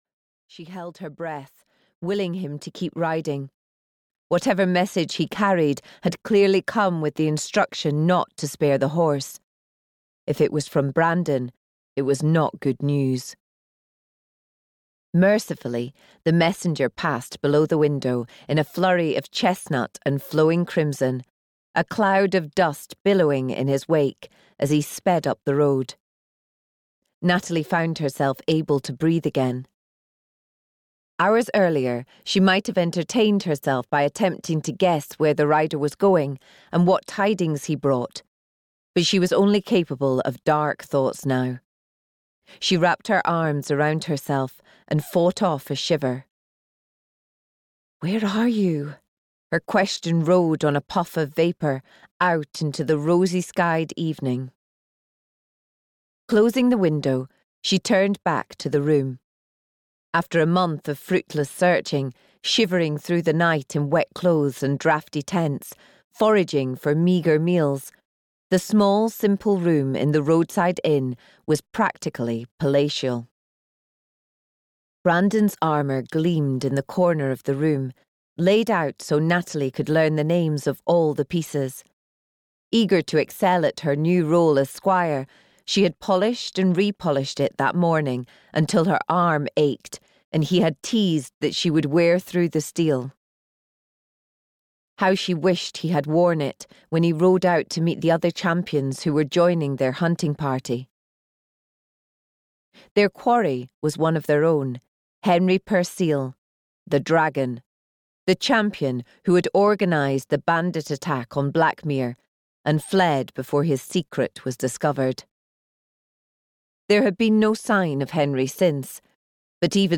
The Champion's Desire (EN) audiokniha
Ukázka z knihy